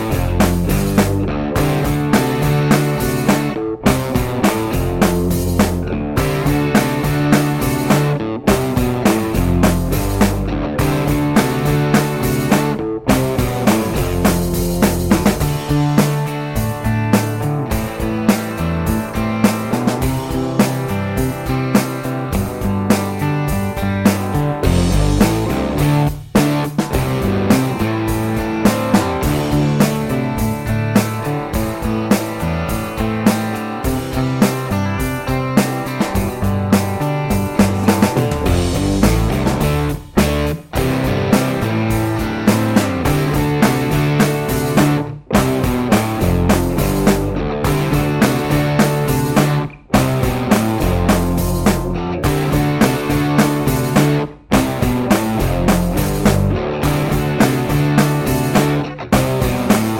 Two Semitones Up Pop (1970s) 2:59 Buy £1.50